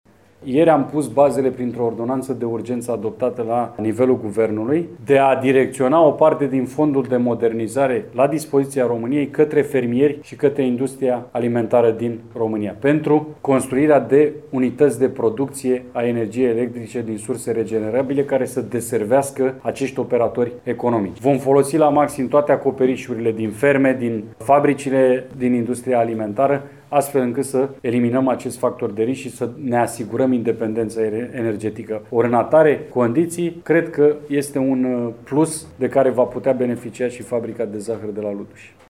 Prezent ieri la Luduș unde a discutat cu reprezentanții cultivatorilor de sfeclă din zona Mureș soluții pentru salvarea fabricii de zahăr, Ministrul Agriculturii, Adrian Chesnoiu a anunțat că și fermierii vor putea beneficia de prevederile Ordonanței de Urgență adoptată de Guvern, pentru implementarea și gestionarea resurselor aflate la dispoziția României din Fondul pentru Modernizare.